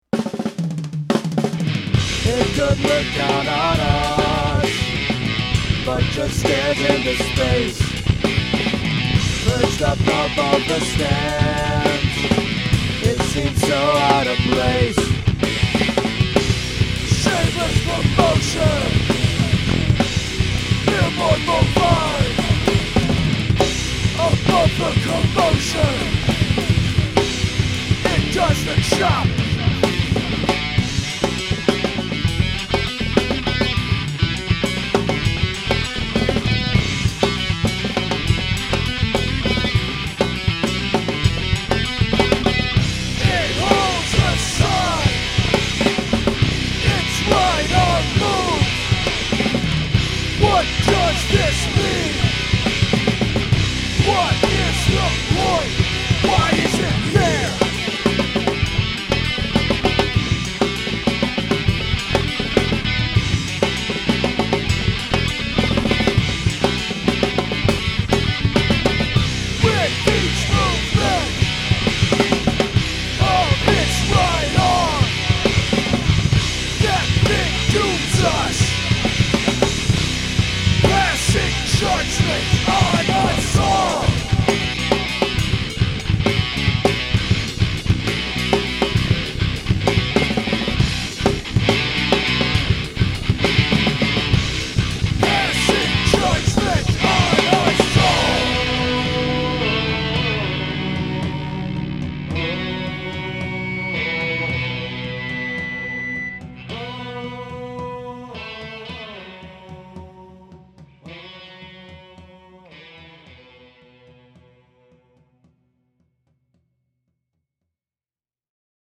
In order to really capture what I like about them, I aped one of their tunings (low to high: B A D G B E), went ape on the drums, and wrote about a big lurking animatronic thing in the city’s ballpark (not an ape).